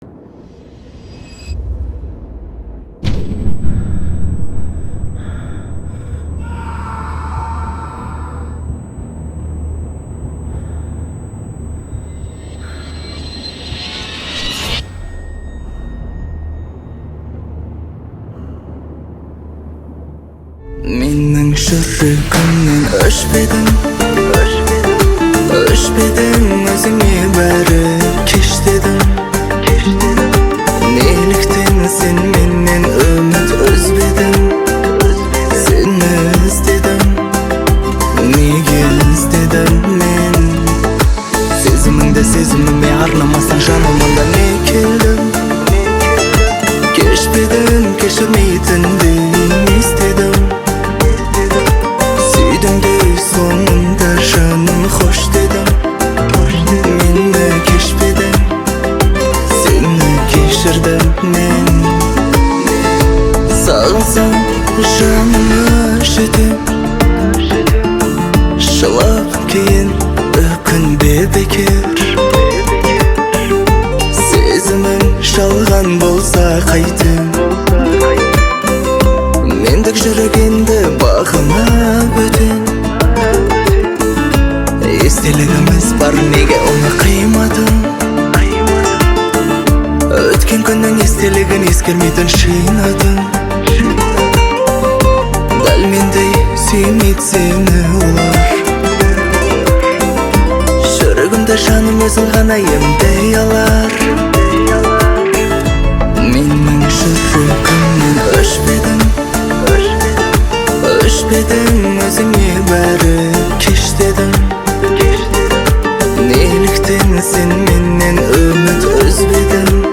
Скачать музыку / Музон / Казахская музыка 2024